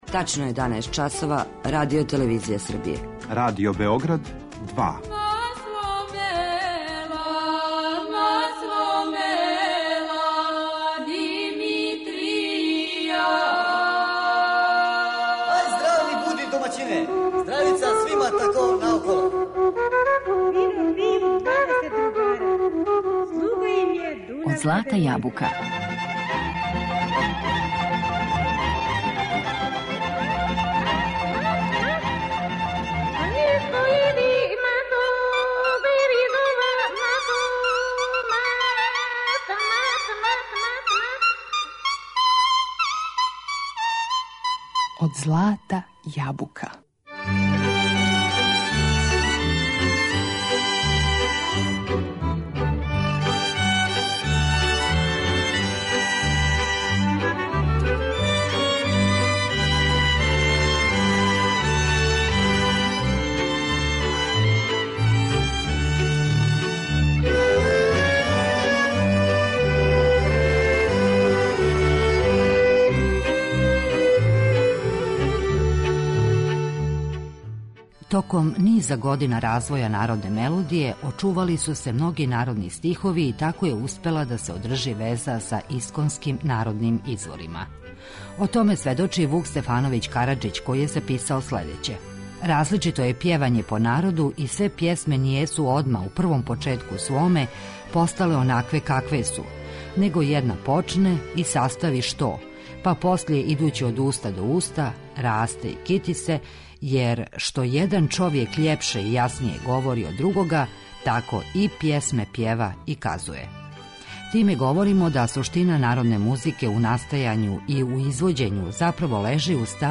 Народне песме у различитим интерпретацијама